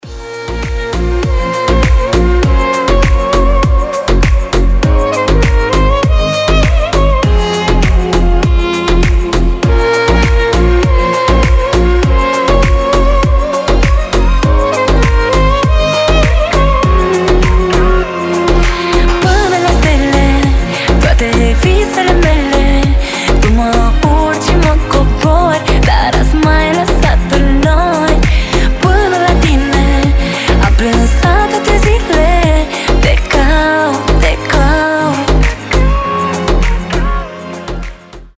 • Качество: 320, Stereo
женский вокал
deep house
восточные мотивы
красивая мелодия